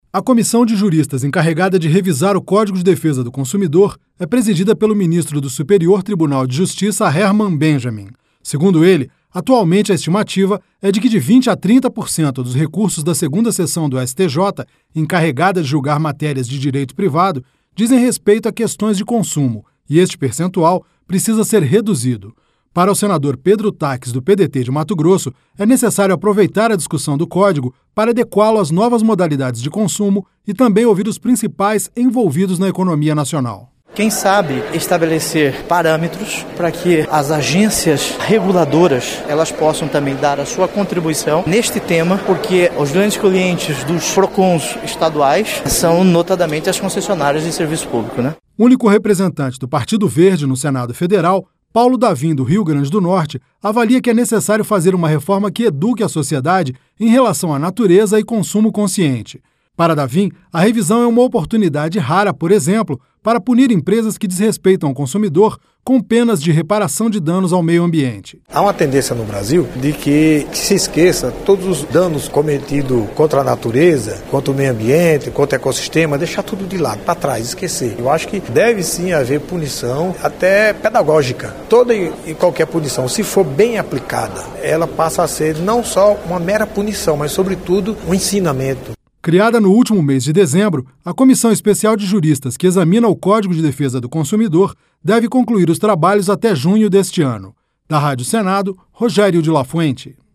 Senador Pedro Taques